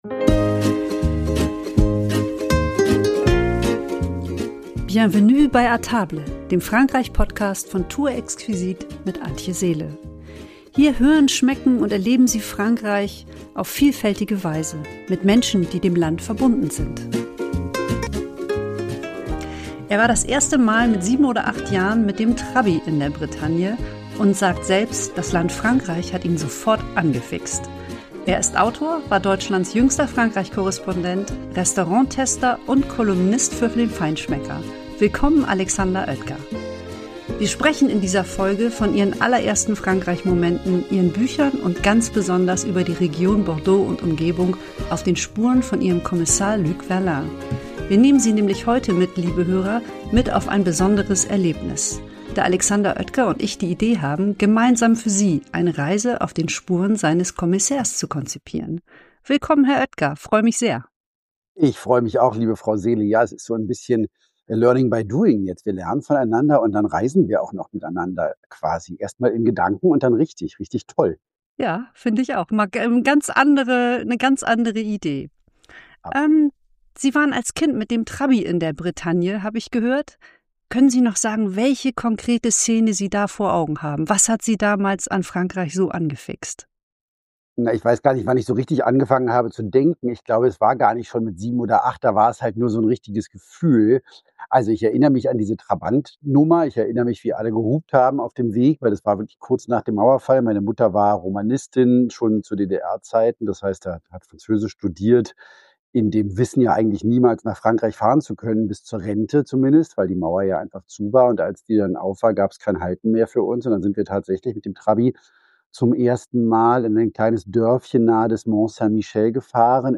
Beschreibung vor 3 Monaten In dieser Episode ist Alexander Oetker zu Gast – Autor und ehemaliger Frankreich-Korrespondent. Wir sprechen über seine Beziehung zu Frankreich und darüber, wie Bordeaux, Arcachon, das Médoc und Sauternes zu Schauplätzen seiner Romane werden.